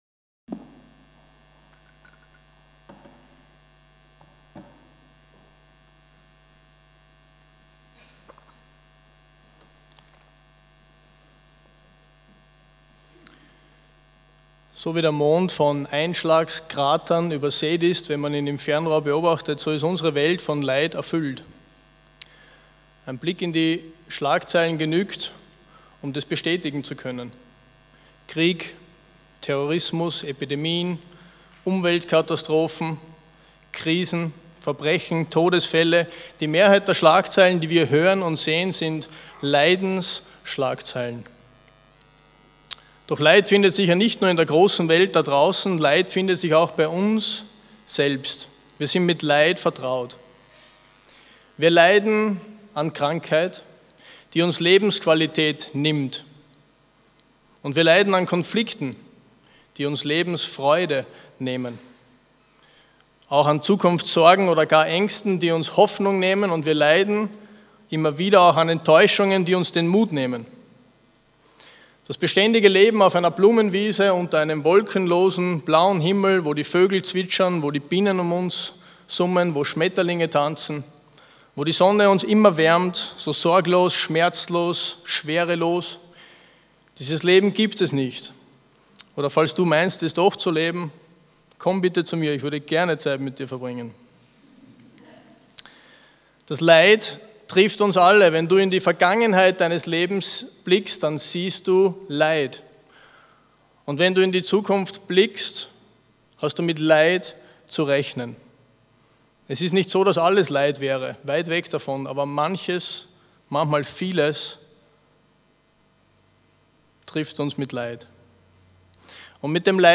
Predigtreihe: Ruth